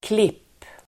Uttal: [klip:]